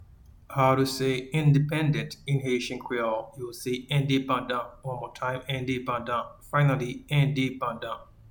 Pronunciation:
Independent-in-Haitian-Creole-Endepandan.mp3